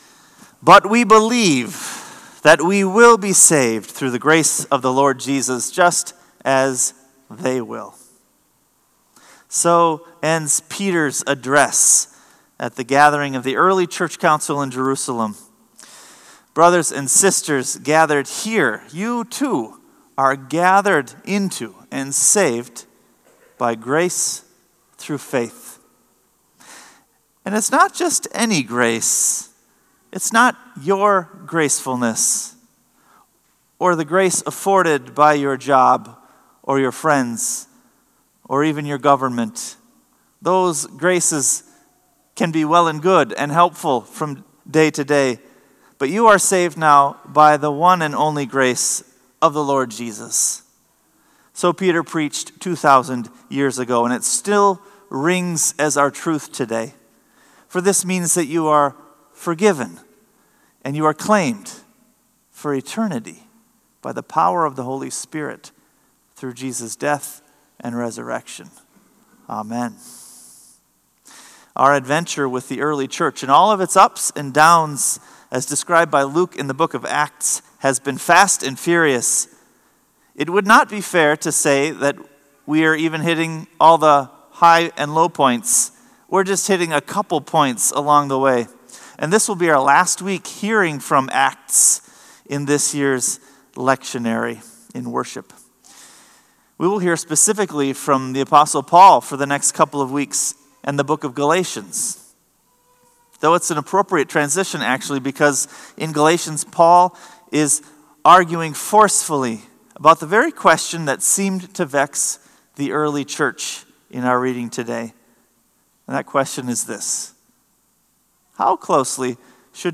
Sermon “A Light for Revelation!”